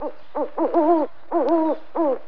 FX - OWL.wav